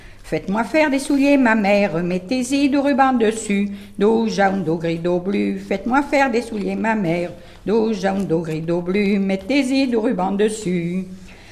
Divertissements d'adultes - Couplets à danser
branle : courante, maraîchine
Pièce musicale inédite